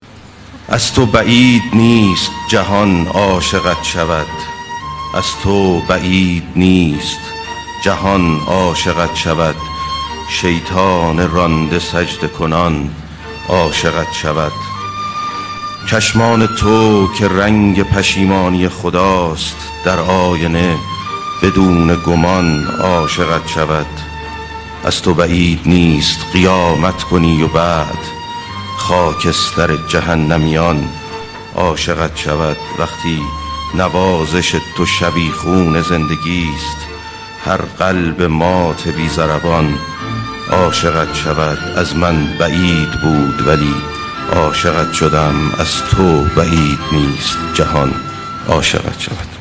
دانلود دکلمه از تو بعید نیست جهان عاشقت شود با صدای افشین یداللهی